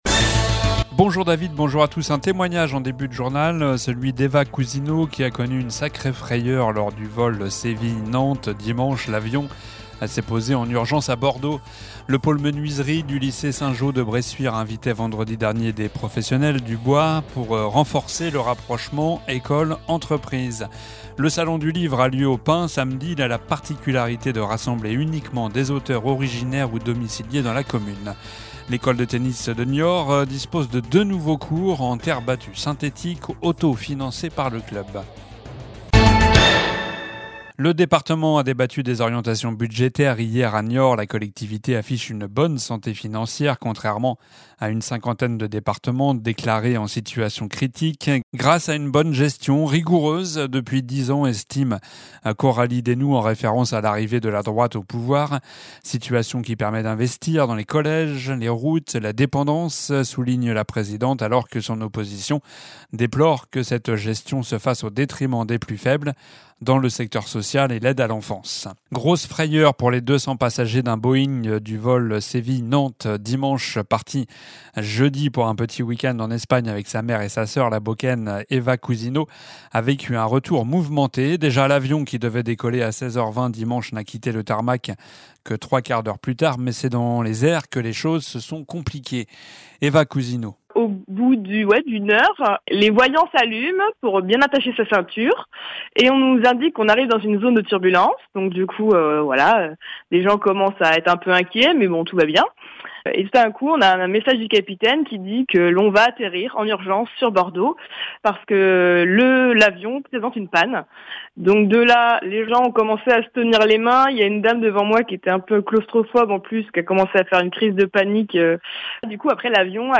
Journal du mardi 25 novembre (midi)